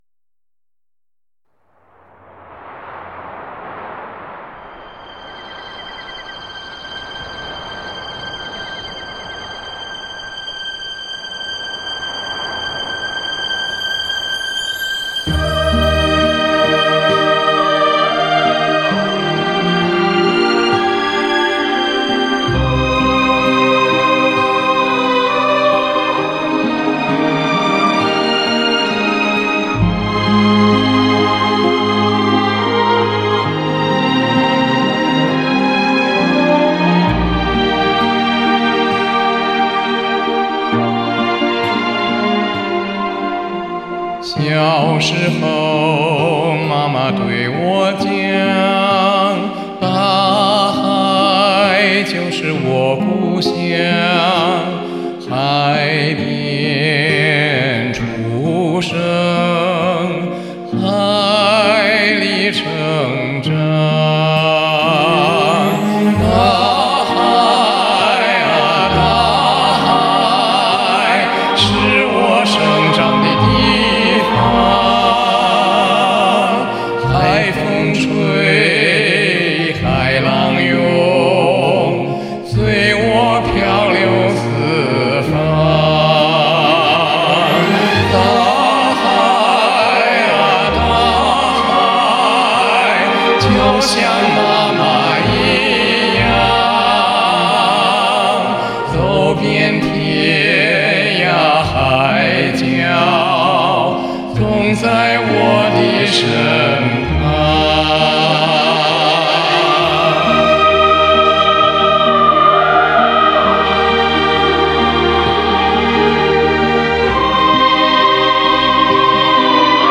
我的音域比较宽，高音有时比高音部的歌友还能飙。这样对我自己唱二声部的组合就很方便。
由于我是唱低声部为主，所以在最后混缩时会把低音声部调的比较响：）